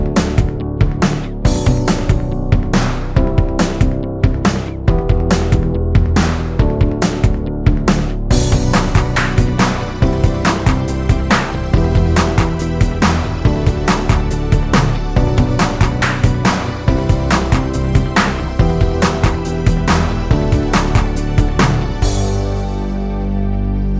no Backing Vocals Pop (2010s) 3:50 Buy £1.50